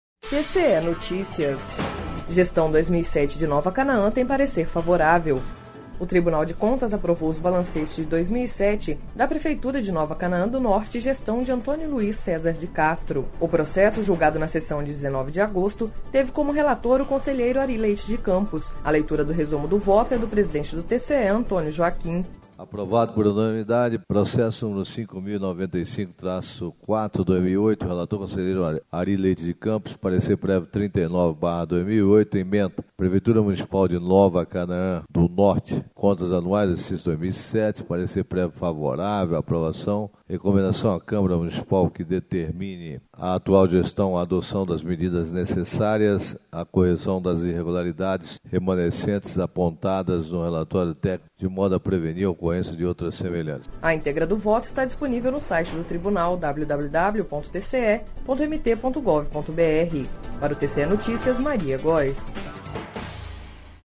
A leitura do resumo do voto é do presidente do TCE, Antonio Joaquim.